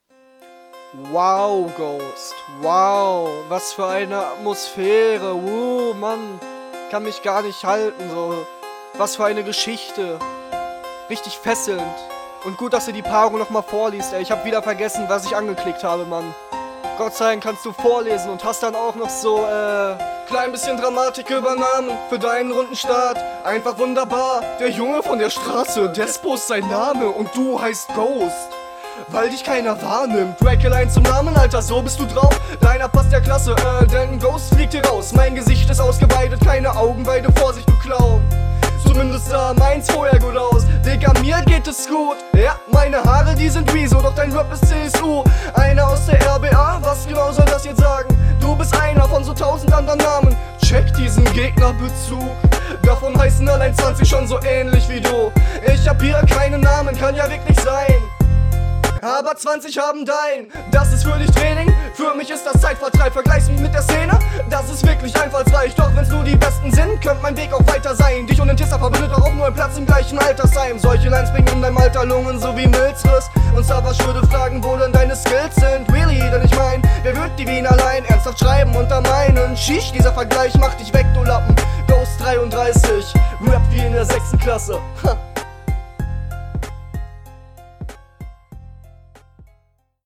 Flow ist nice!
Stimme auch nicht so geil wie bei deinem Gegner.